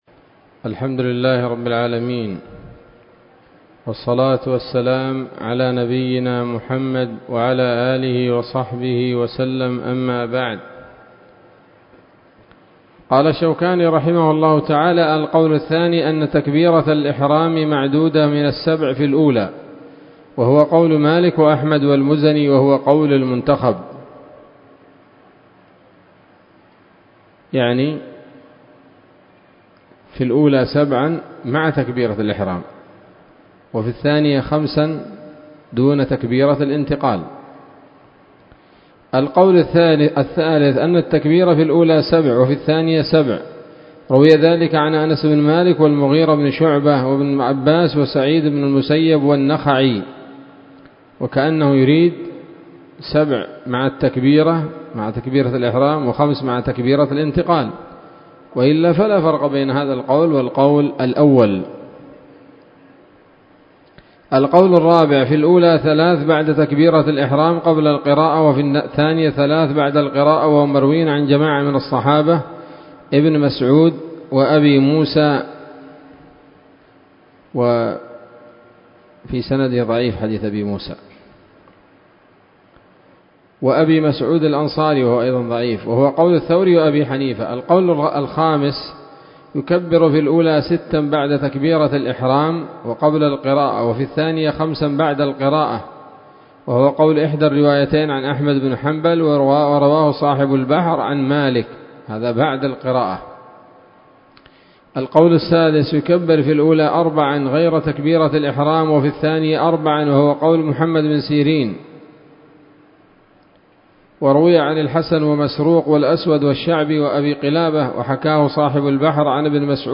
الدرس الثاني عشر من ‌‌‌‌كتاب العيدين من نيل الأوطار